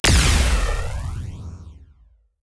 Alien weapon/blast sounds
22khz mono already.